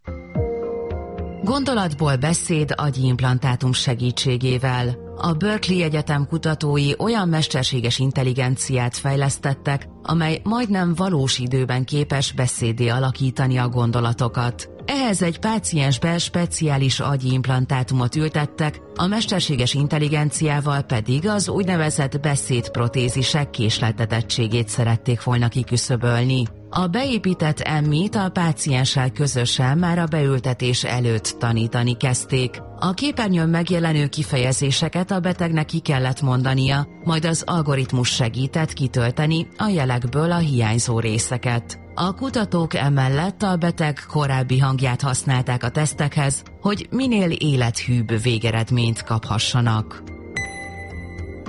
· Gondolatból beszéd – agyi implantátum segítségével. Rövid hír a
rádióból: *Gondolatból beszéd.mp3*